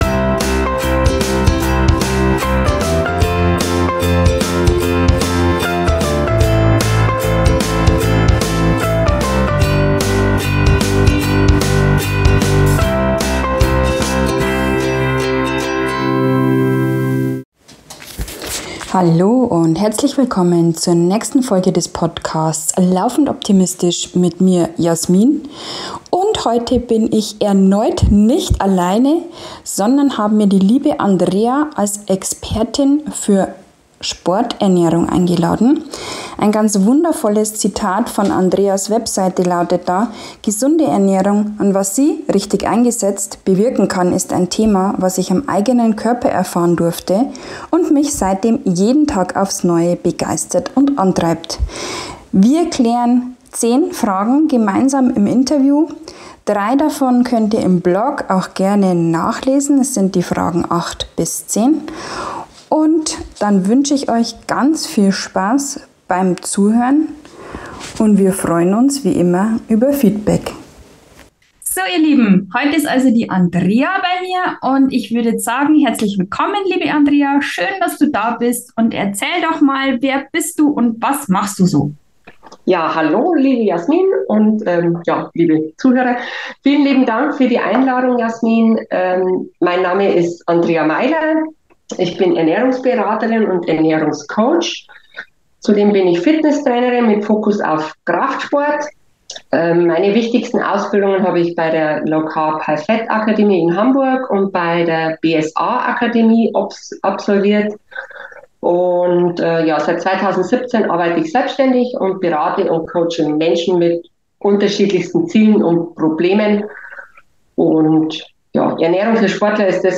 Beschreibung vor 1 Jahr Wow bereits die dritte Interview Folge hintereinander.